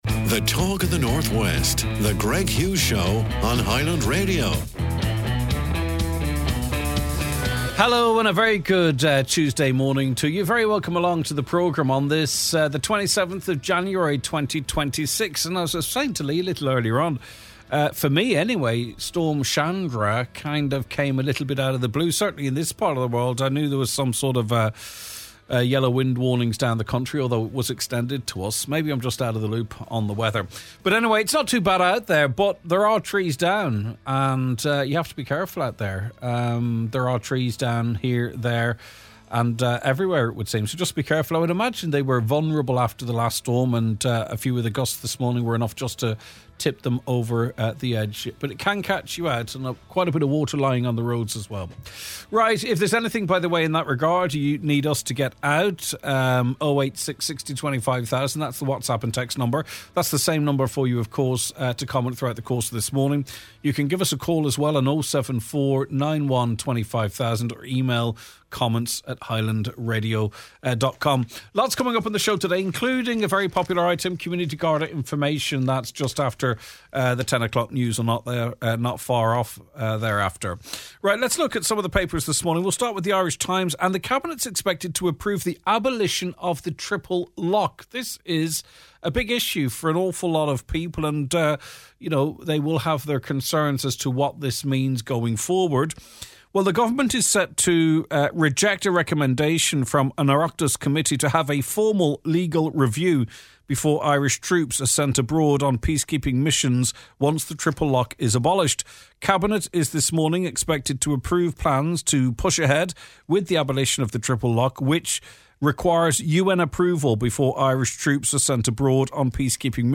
Retail Safety Crisis: Mandate Trade Union speaks out on the alarming rise in abuse and physical threats faced by retail workers across the country. Trad Week Live: